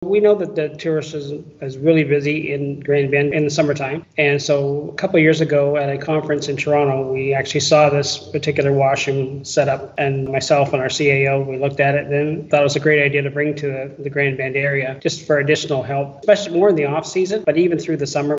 Lambton Shores Mayor, Doug Cook says the idea for the new washroom was inspired by a similar unit seen at a municipal conference, which received positive feedback.